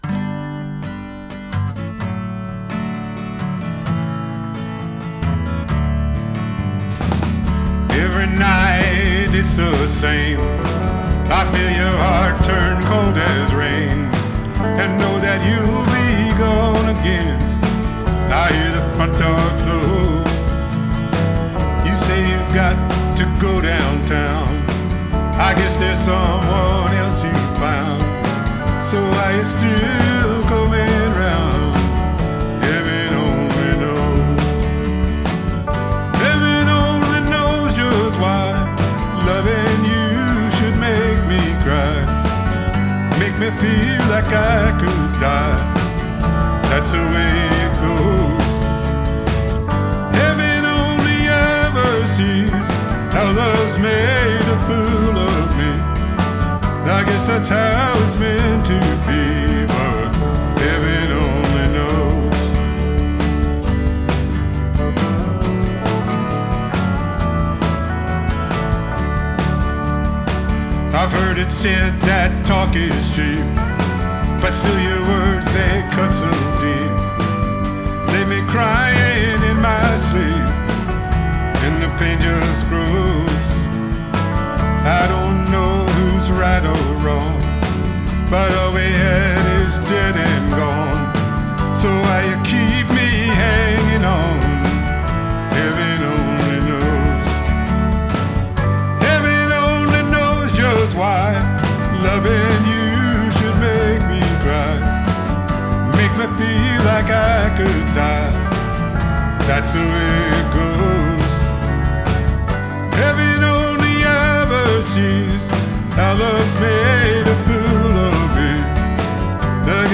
Here’s my cover.